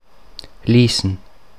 Deutsch-mundartliche Form
[liːsn]
Lüsen_Mundart.mp3